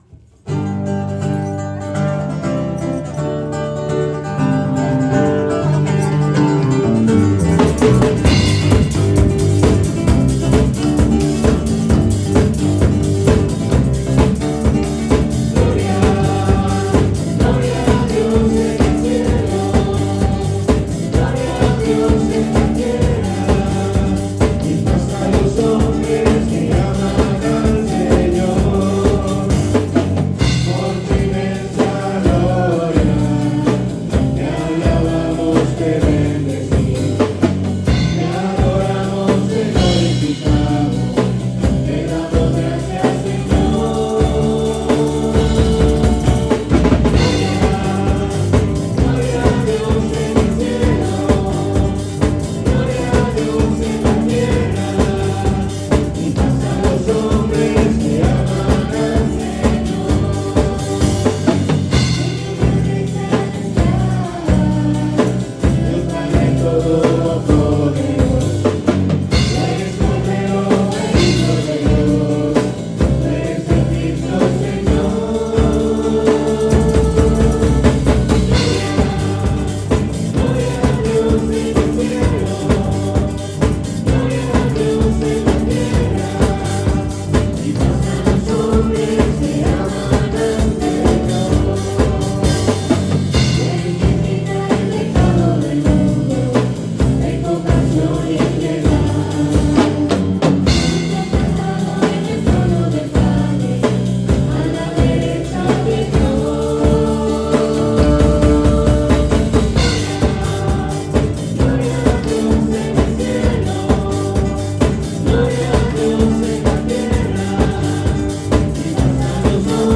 Ritmo: 45
Tempo: 130
Audio: Coro Shaaday
Gloria-pop.mp3